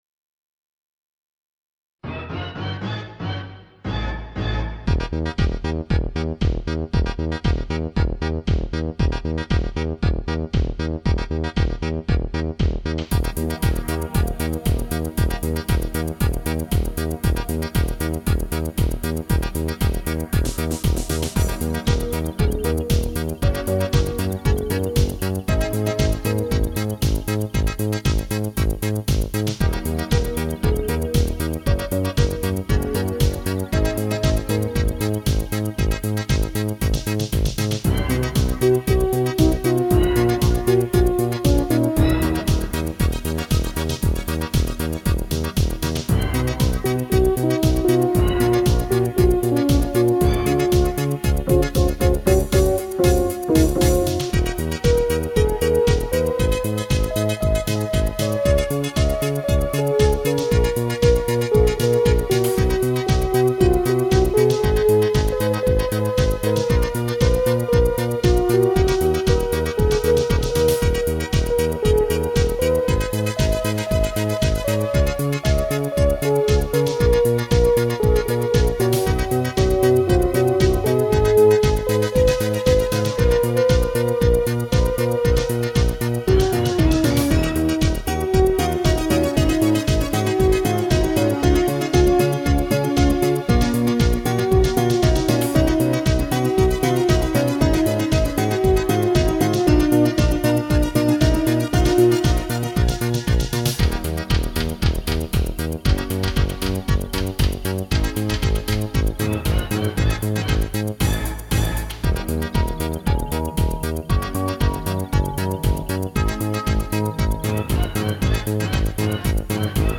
Со всеми вытекающими последствиями в плане звука.